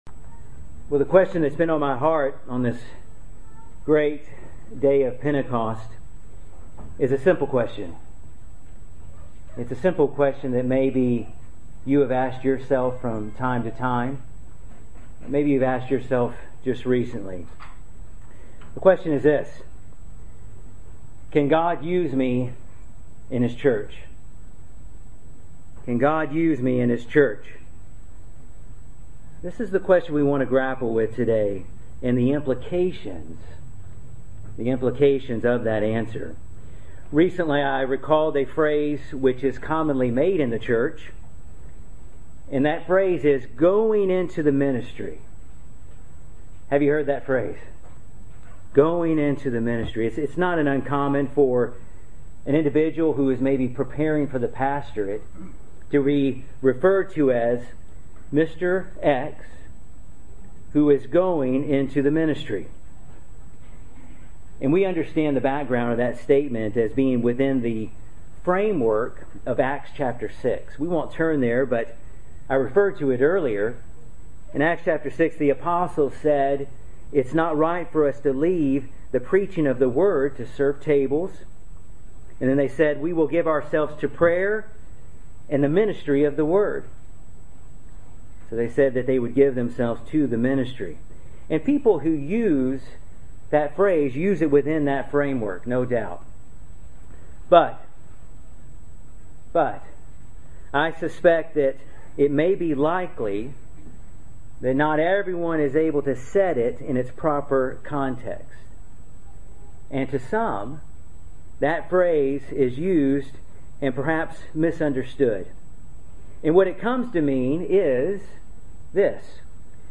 Great Sermon for Pentecost explaining that everyone in God's church has a ministry. This sermon was given in Berea, Ky at a combined service for the Louisville, Lexington, and London Kentucky congregations on the day of Pentecost, 2017